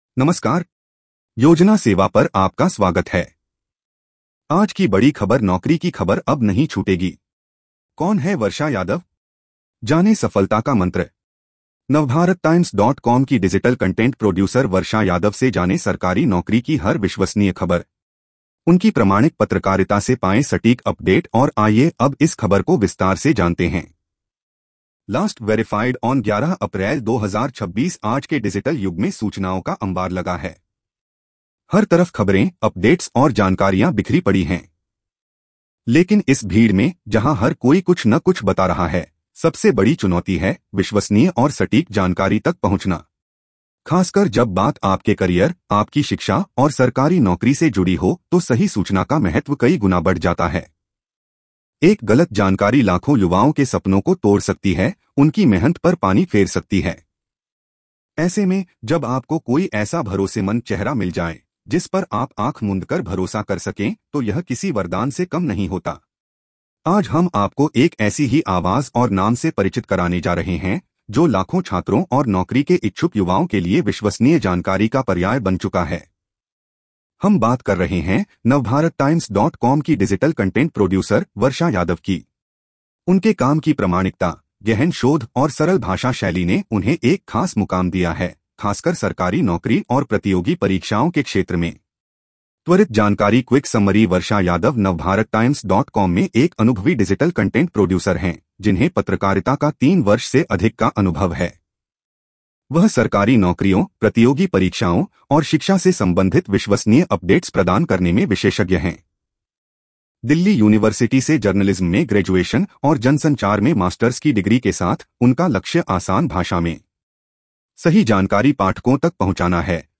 News Audio Summary
🎧 इस खबर को सुनें (AI Audio):